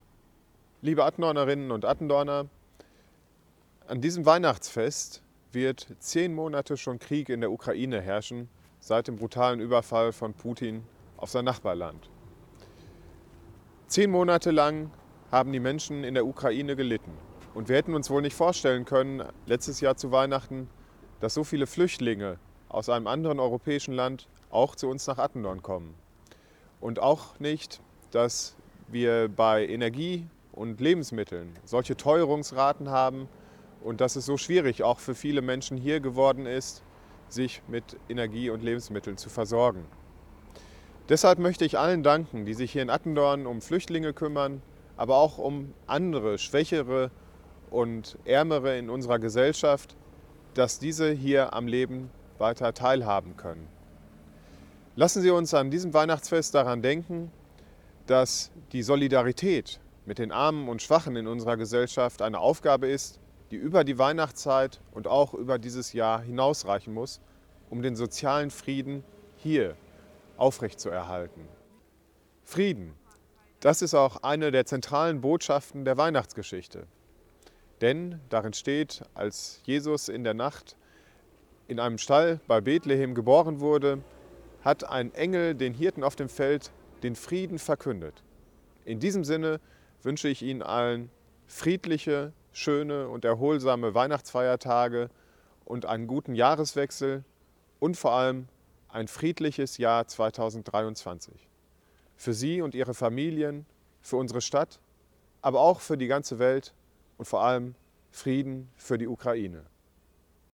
Bürgermeister Christian Pospischil wünscht allen Bürgerinnen und